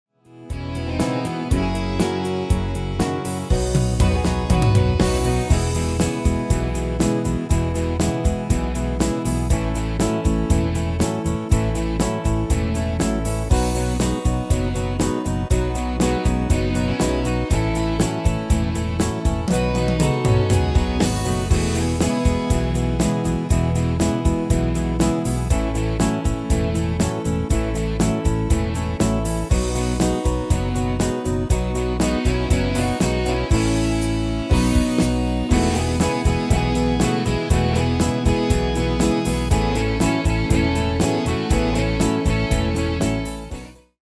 Demo/Koop midifile
Genre: Duitse Schlager
Toonsoort: E
- Vocal harmony tracks